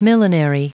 Prononciation du mot millinery en anglais (fichier audio)
Prononciation du mot : millinery